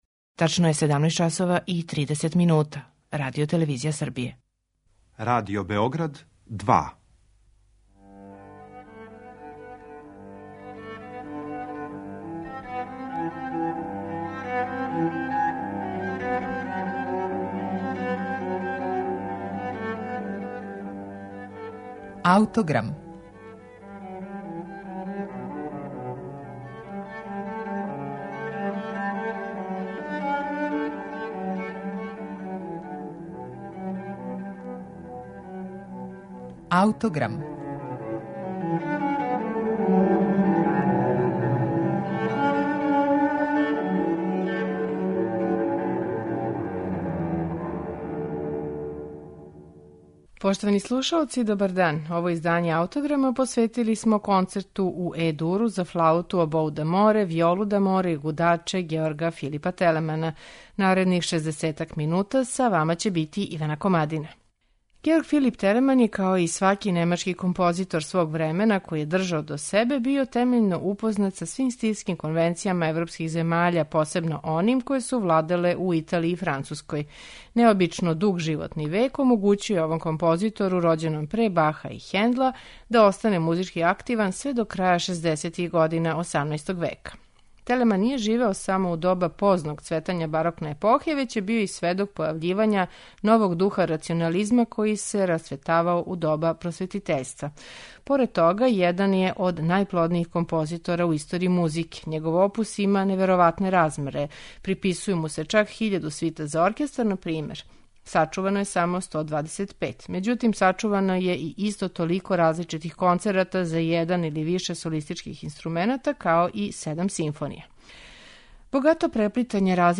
Концерт у Е-дуру за флауту, обоу д'аморе, виолу д'аморе и гудаче, Георга Филипа Телемана, једно је од ремек-дела овог аутора.
Деликатне тонске боје које одликују звук сваког од солистичких инструмената стопљене су са изузетном сензибилношћу и уметничком вештином, одајући композитора који познаје све њихове нијансе.
У овом ставу аутор је маестрално испреплетао епизоде солиста, повезујући их са тематским материјалом изнетим у тути одсецима.